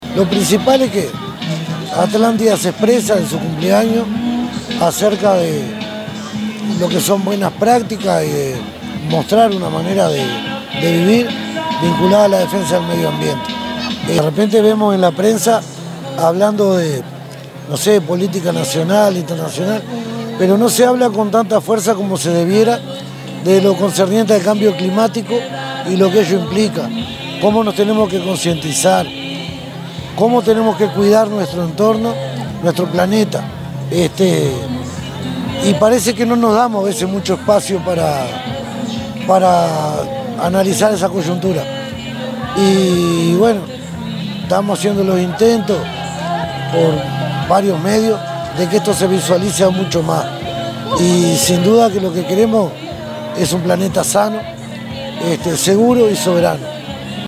gustavo_gonzalez_-_alcalde_de_atlantida.mp3